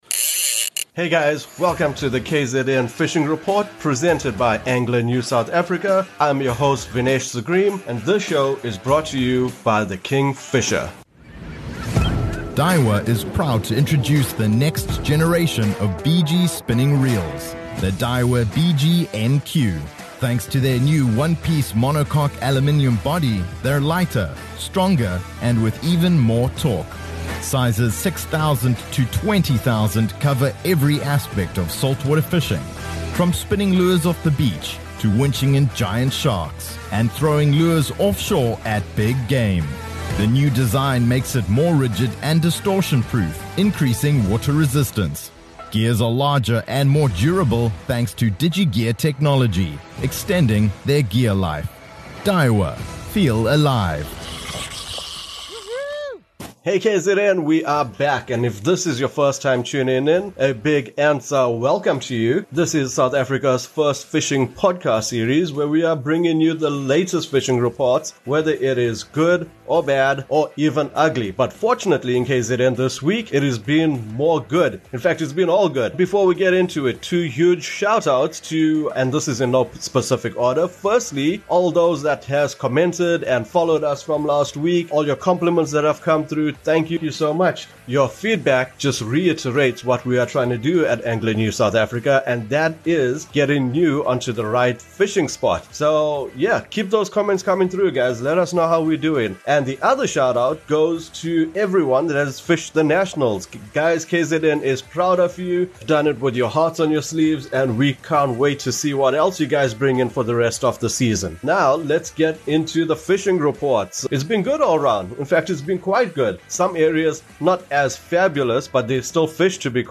There's Zero bad fishing off the KZN Coastline this week, there's something for everyone from Port Edward to Cape Vidal...Listen to the latest report of the East Coast as we are joined by our leading correspondents all across KZN!